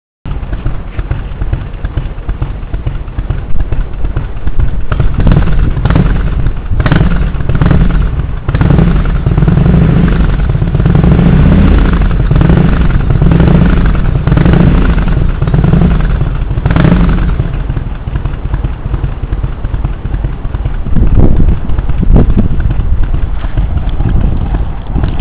結果・・・アイドリングがとても静かになりました。（静かすぎかも？）
ウール装着後アイドリング音